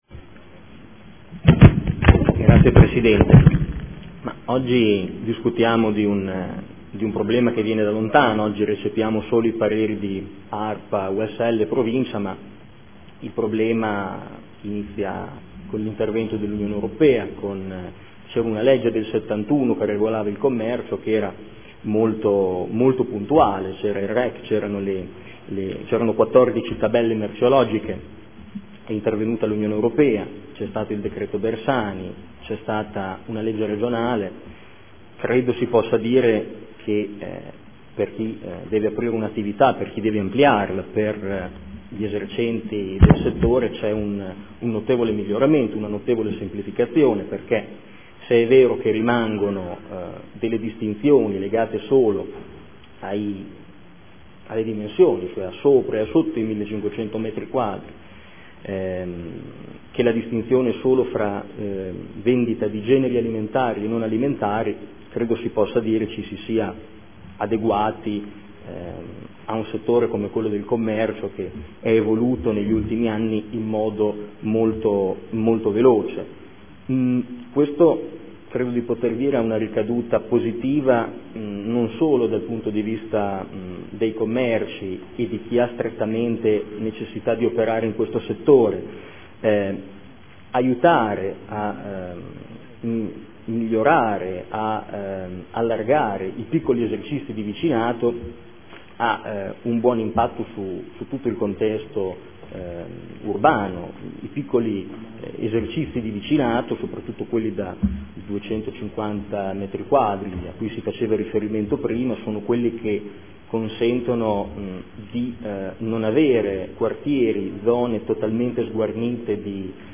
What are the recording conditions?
Seduta del 13/11/2014 Dibattito.